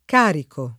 carico [ k # riko ]